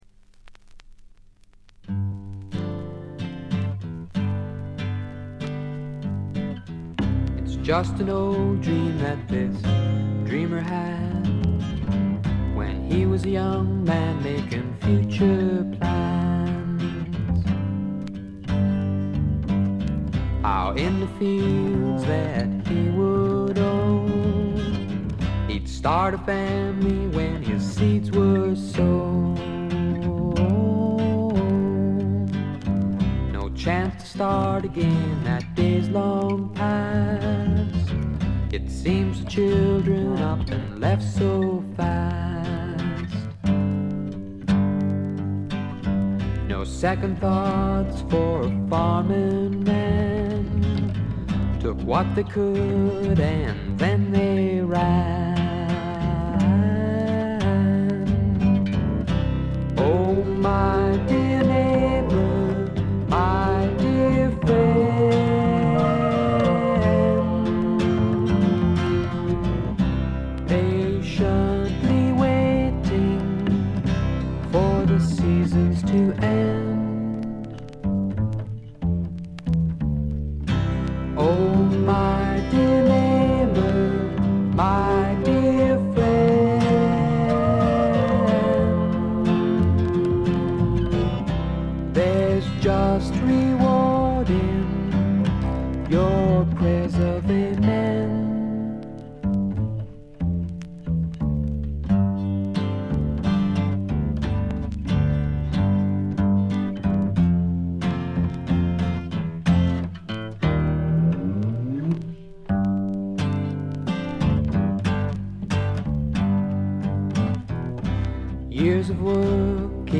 ドラムレスで、Heron的な木漏れ日フォークのほんわか感と、米国製メロー・フォーク的なまろやかさが同居した名作です。
試聴曲は現品からの取り込み音源です。